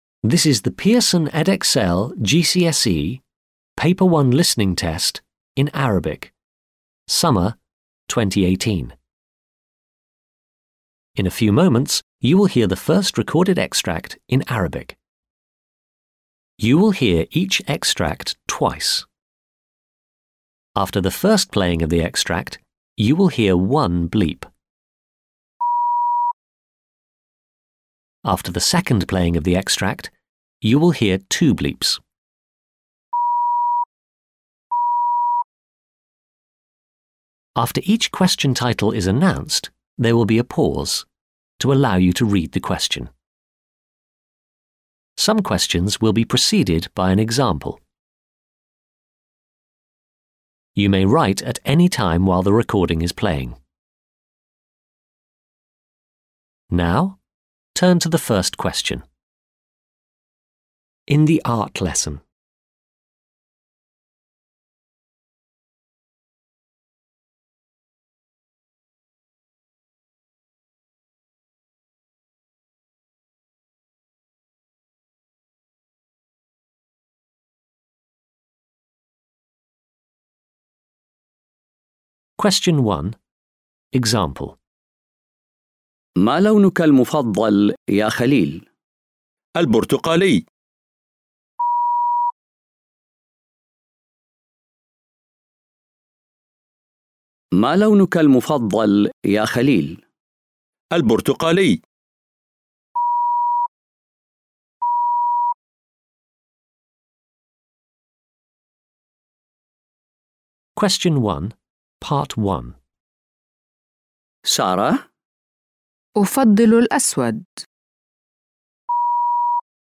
GCSE Arabic – Listening Exam MP3 – Unit 1 – June 2018